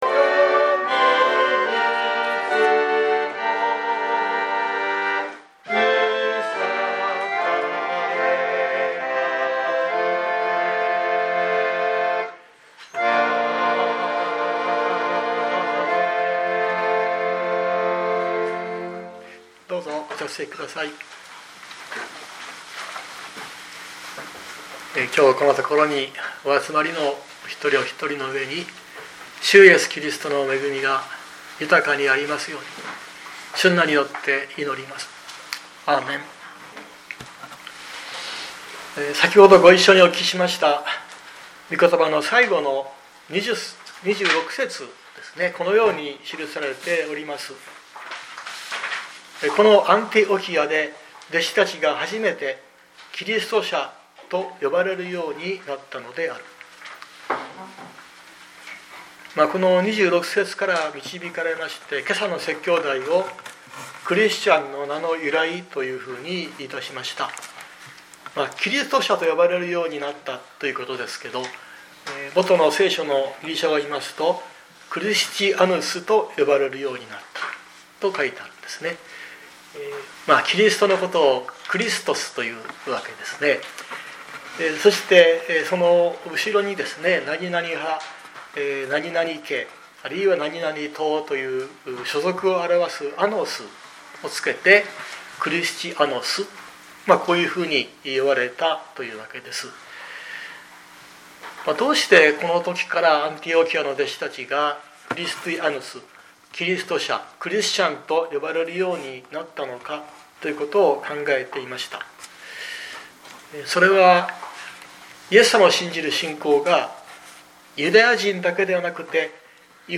2025年05月11日朝の礼拝「クリスチャンの名の由来」熊本教会
説教アーカイブ。